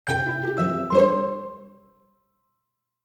notification_008.ogg